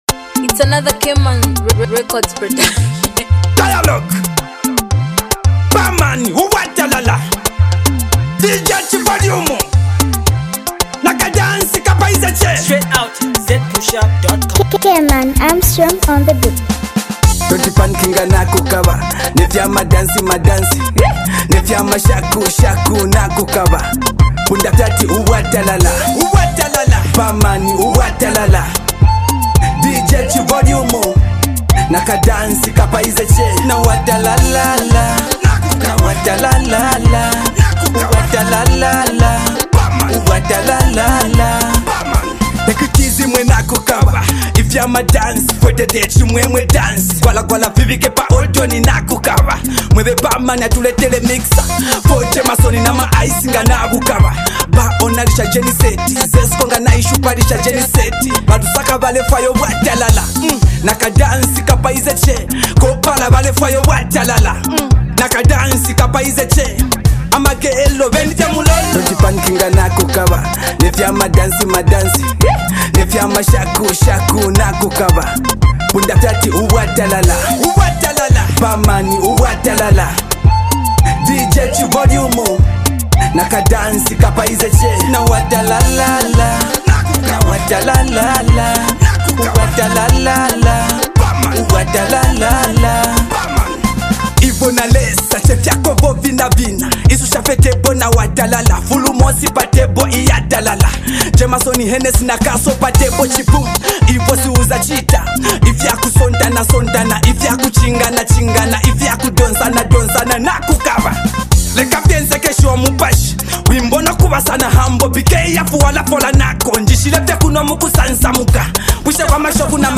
in a dance able way